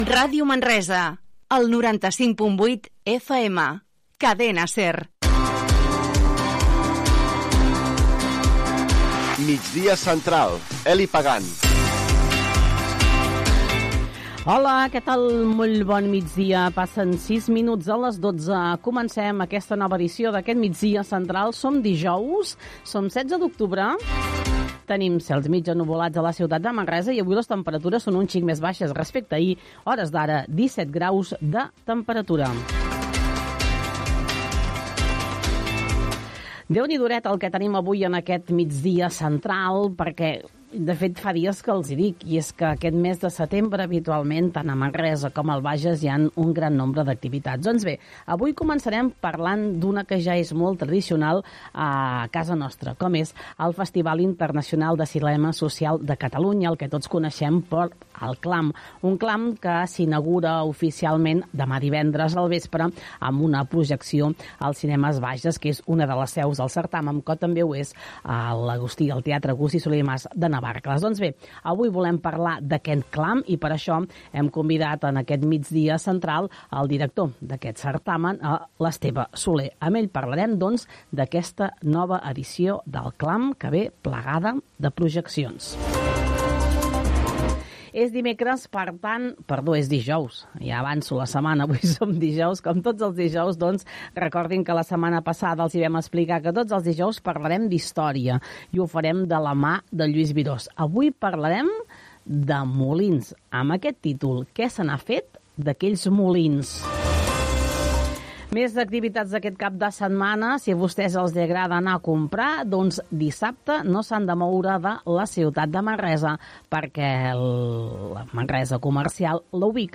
ef3038a2b1f8c0f703c718c2d6ccf0a6dcbb4e71.mp3 Títol Ràdio Manresa FM Emissora Ràdio Manresa FM Cadena SER Titularitat Privada local Nom programa Migdia central Descripció Identificació de la ràdio, careta del programa, hora, data, presentació, activitats del cap de setmana, resum de premsa, el temps, indicatiu, publicitat.
Gènere radiofònic Informatiu